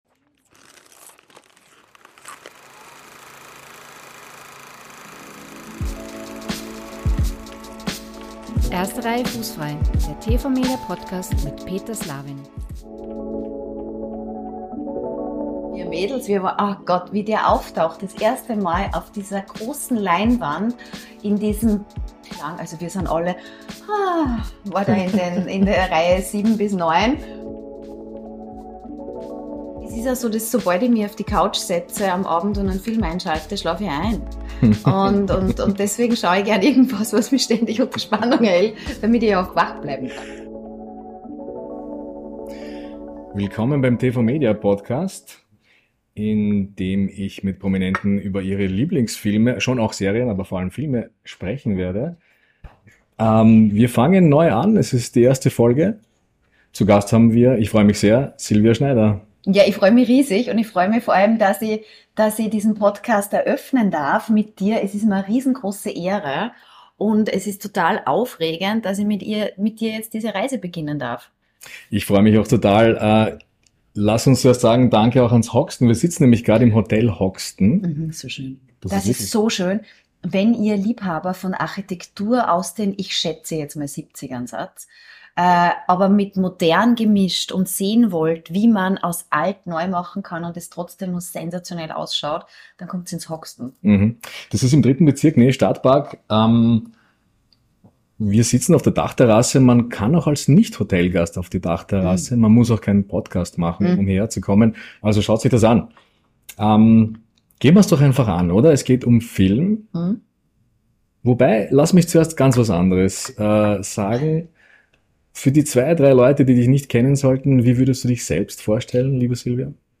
Sie mag Horror, lacht sich über irische Pfarrer kaputt und findet den einen polnischen Synchronsprecher großartig. Ein Gespräch im Wiener Hotel The Hoxton.